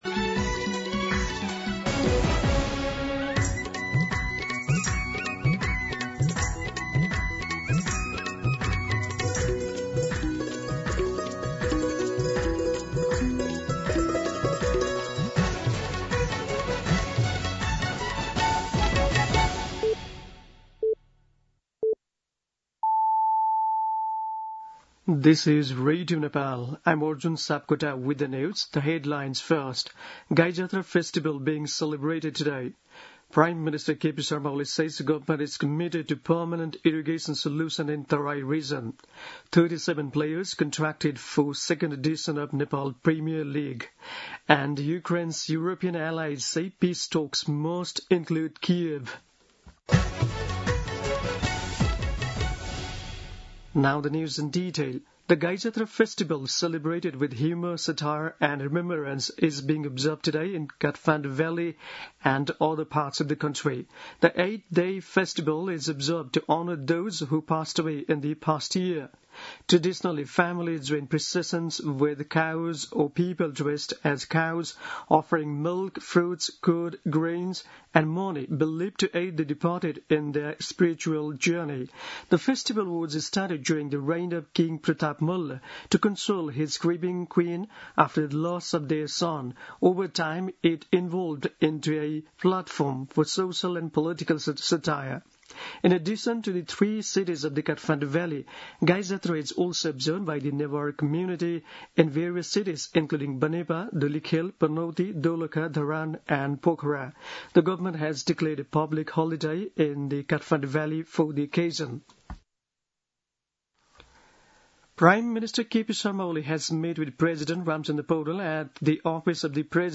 दिउँसो २ बजेको अङ्ग्रेजी समाचार : २५ साउन , २०८२
2-pm-english-News.mp3